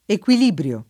vai all'elenco alfabetico delle voci ingrandisci il carattere 100% rimpicciolisci il carattere stampa invia tramite posta elettronica codividi su Facebook equilibrio [ ek U il & br L o ] s. m.; pl. ‑bri (raro, alla lat., ‑brii )